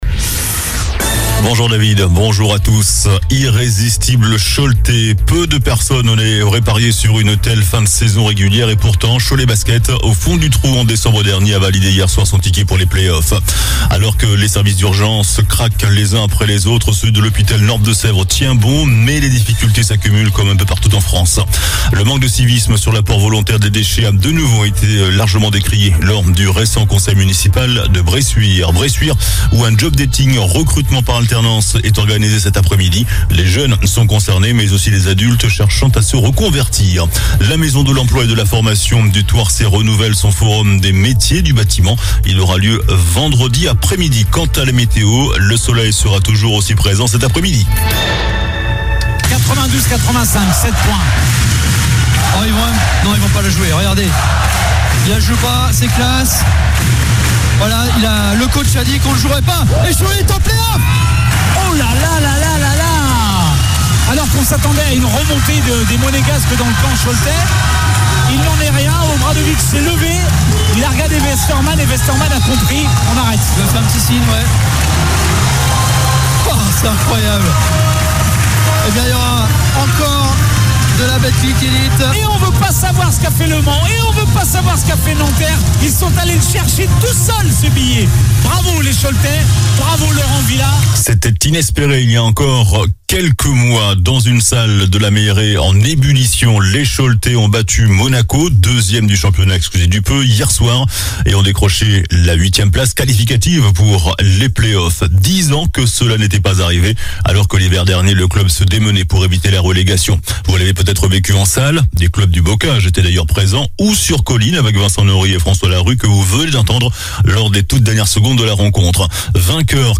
JOURNAL DU MERCREDI 18 MAI ( MIDI )